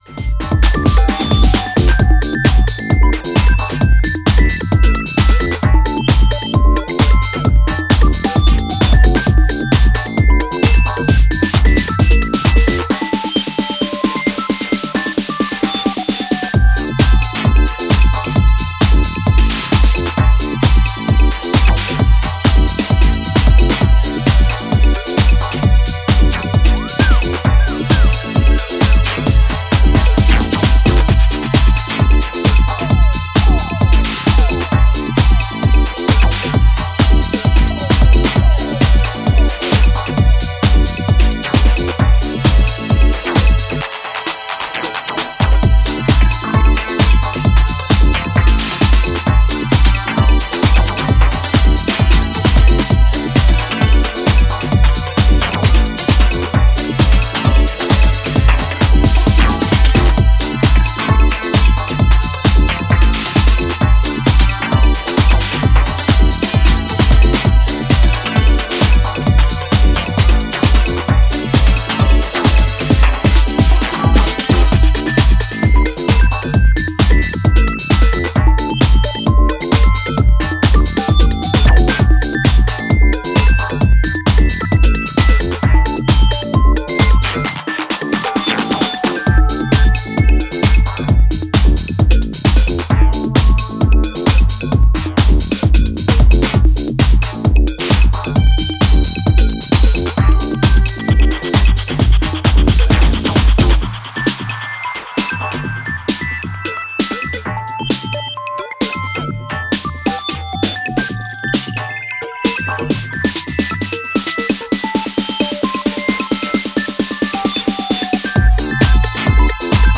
metallic FM-synthesis driven sounds
Deep, moody and uplifting.